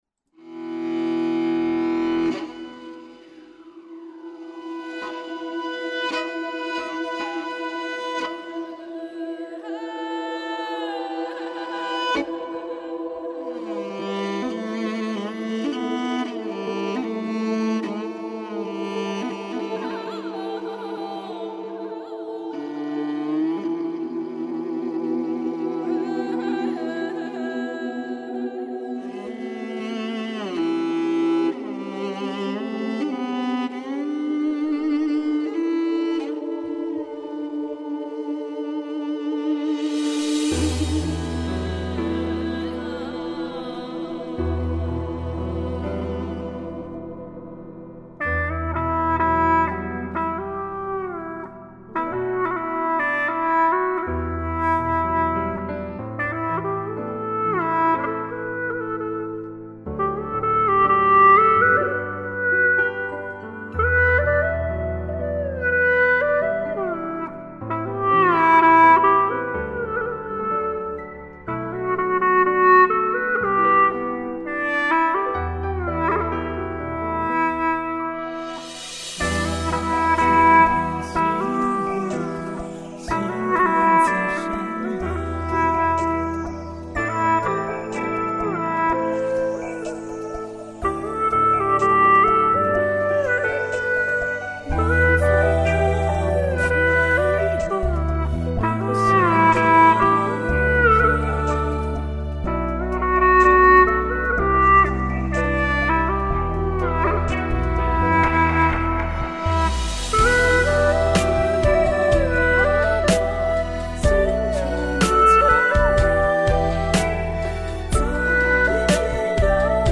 调式 : G